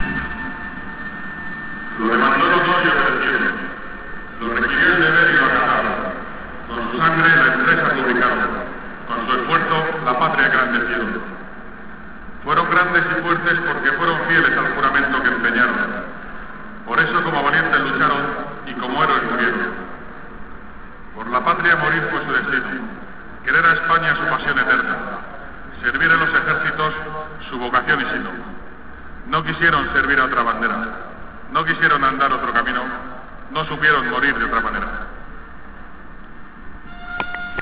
Locución a los que dieron su vida por España
locucion_vida_por_espana_diahisp.wav.wav